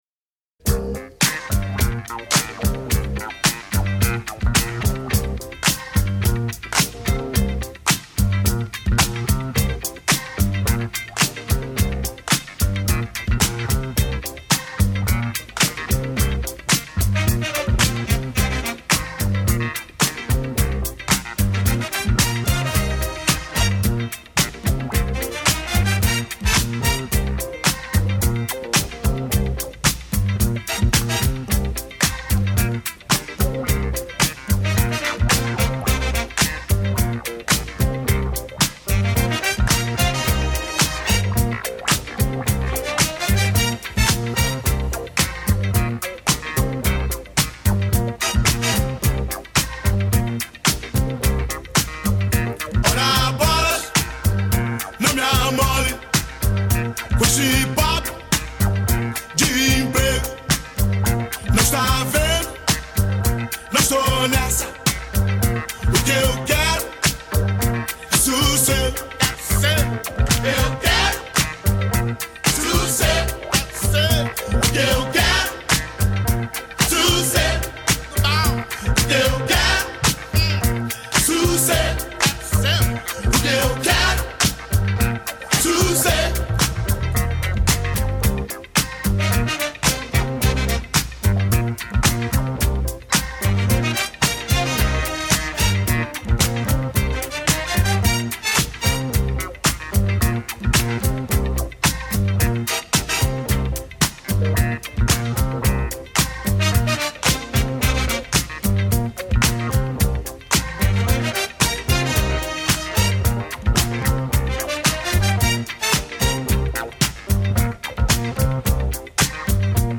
here’s a pitch-corrected file]